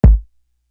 Mpk Kick.wav